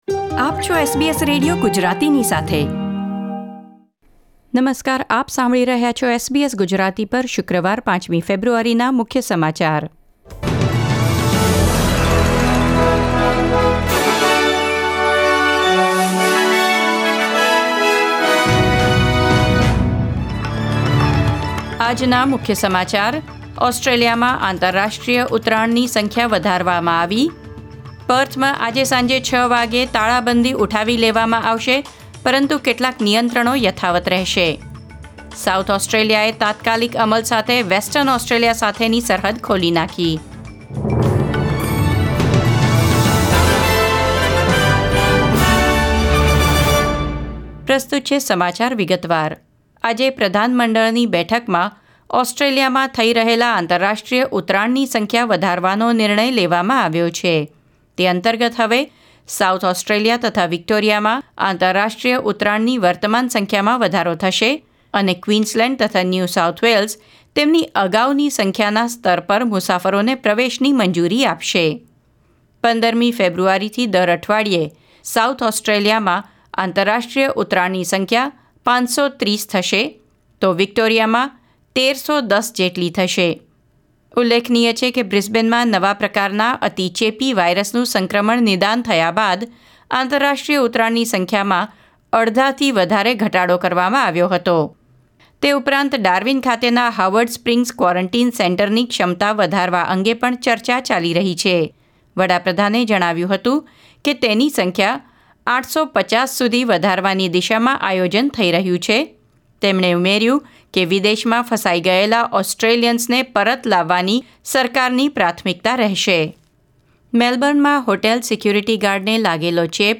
SBS Gujarati News Bulletin 5 February 2021